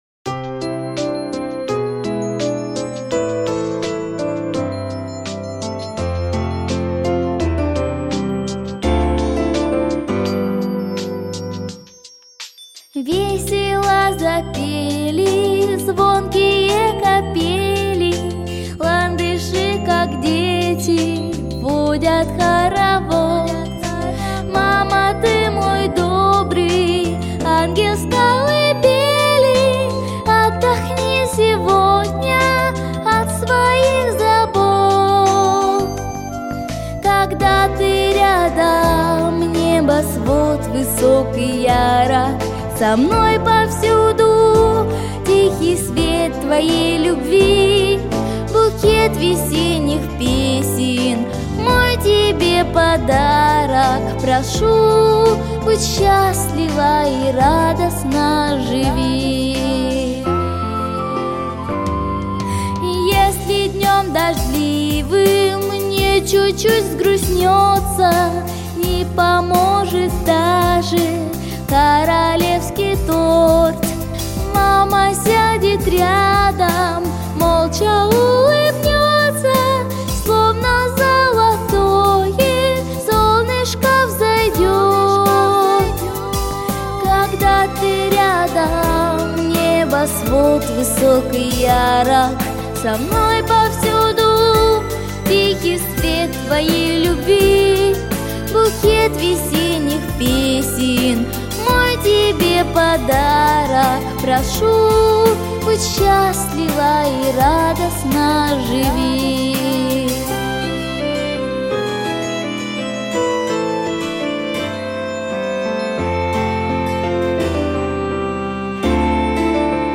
🎶 Детские песни / О близких людях / Песни про маму